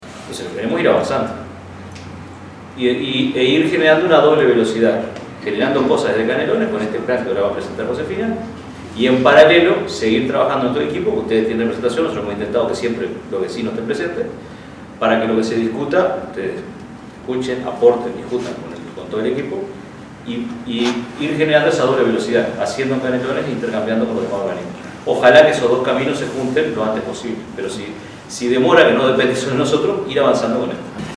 En la Ludoteca del parque Roosevelt, se llevó a cabo la presentación del Programa de Restauración Ecológica para la costa de Canelones.